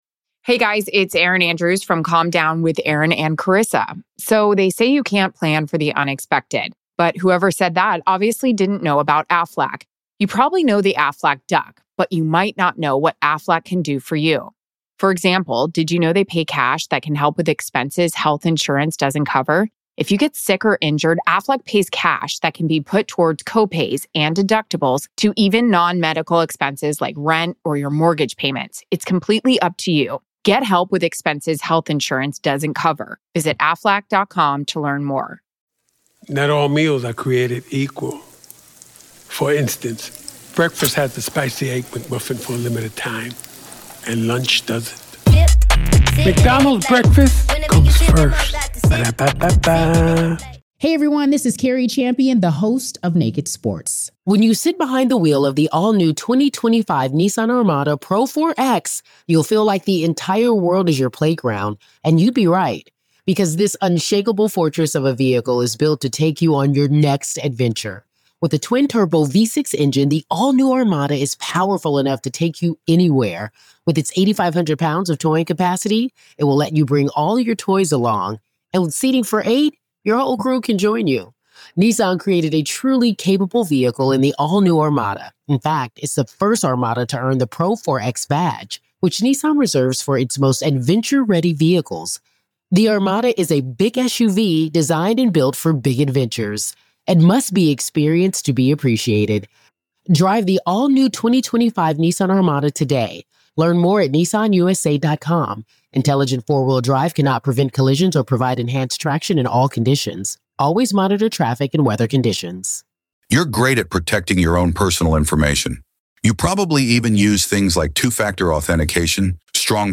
True Crime Today | Daily True Crime News & Interviews / Does Gilgo Beach Killer's Murder Spree Span Multiple States?